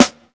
• Sharp Steel Snare Drum Sample B Key 124.wav
Royality free snare drum sample tuned to the B note. Loudest frequency: 2048Hz
sharp-steel-snare-drum-sample-b-key-124-NyO.wav